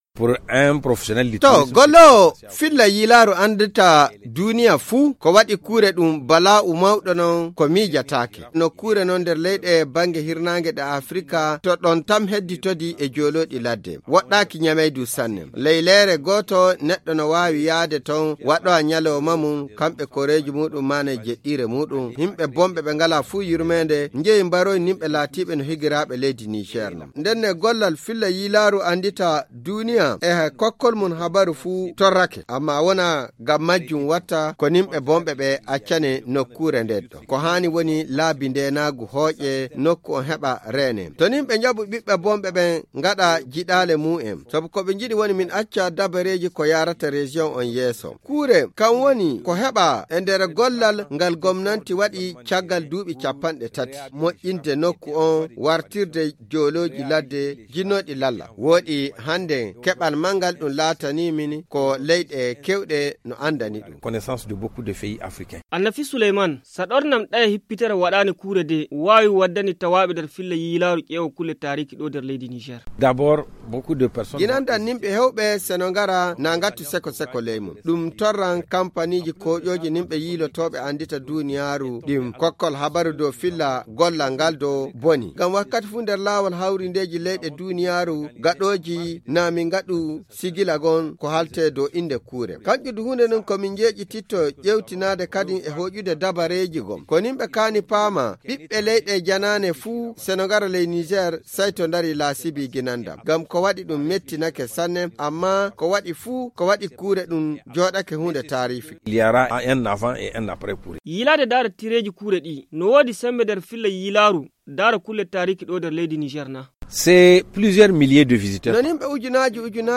Le magazine en fulfuldé